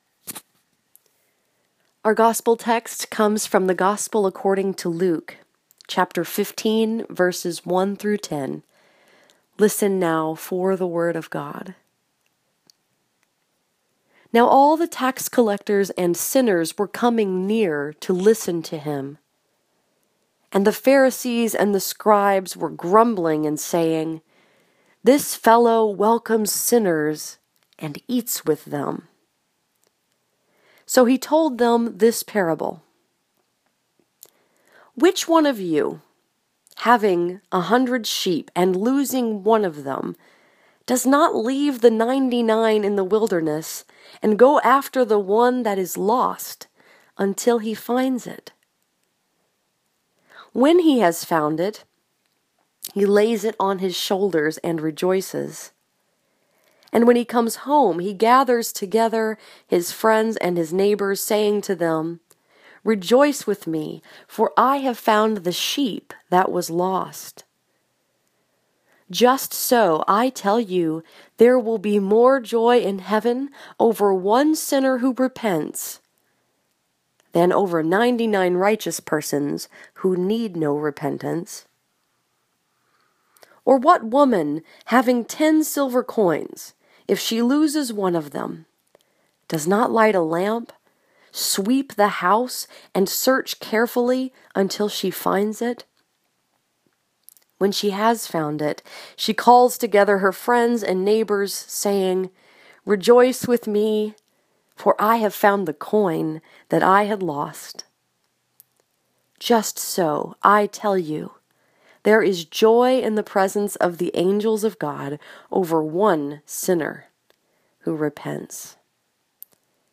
This sermon was preached at New Life Presbyterian Church in Sterling Heights, Michigan and was focused upon Luke 15:1-10.